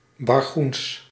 Bargoens (Dutch pronunciation: [bɑrˈɣuns]
Nl-Bargoens.ogg.mp3